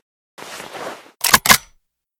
/ gamedata / sounds / weapons / remington870 / close_empty.ogg 22 KiB (Stored with Git LFS) Raw History
close_empty.ogg